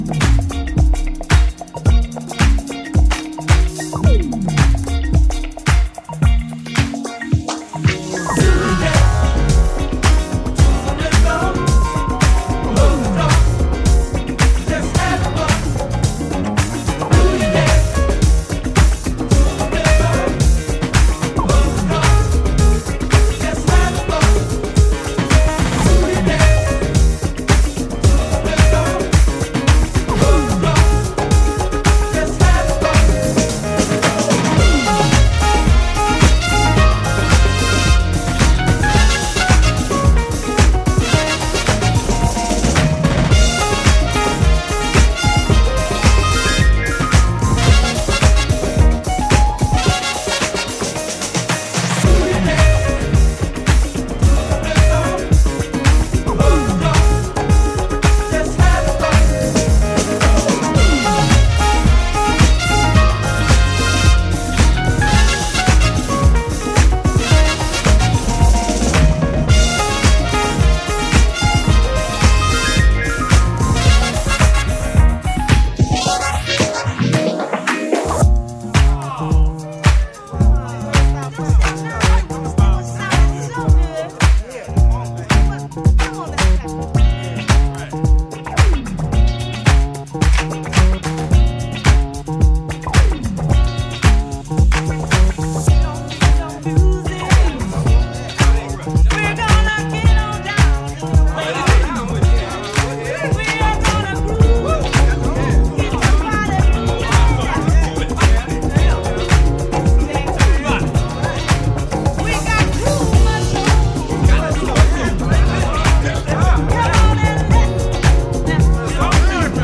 funky modern boogie songs with a strong re-edit color